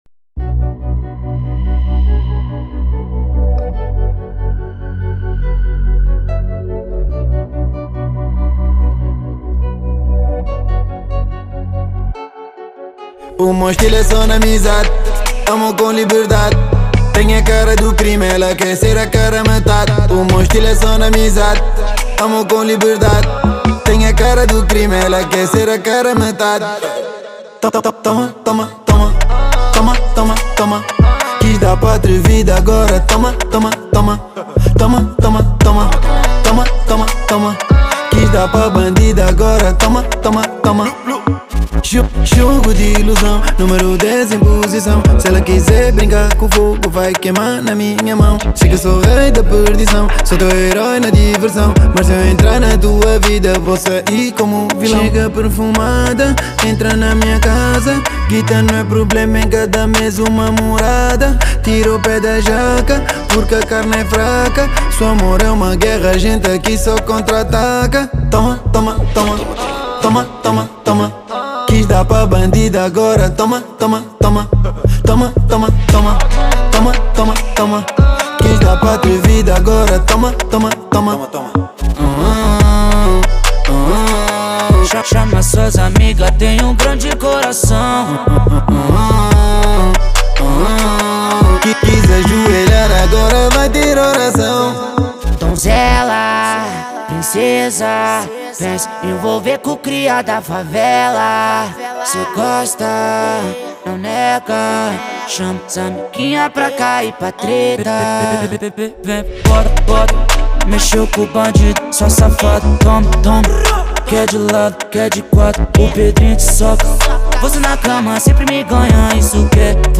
Genero: Drill